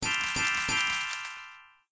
threeSparkles.ogg